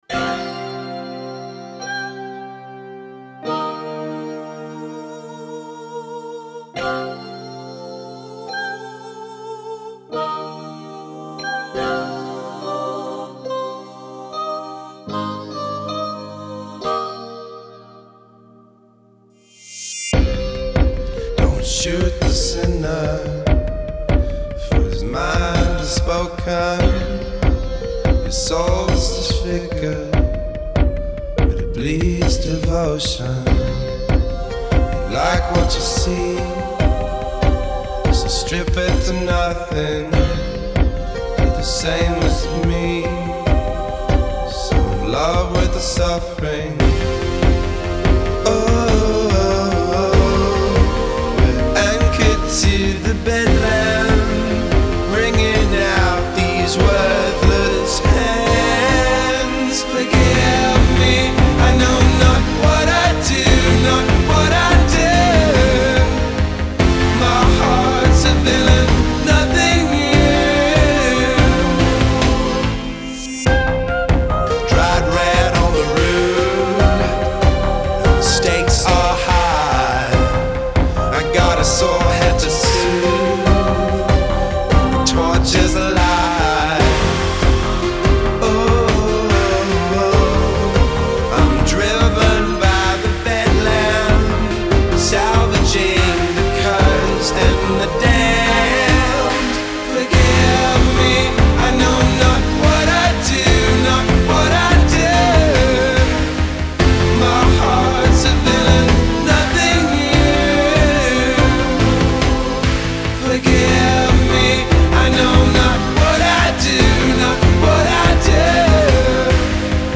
Жанр: Electronic.